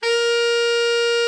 TENOR 27.wav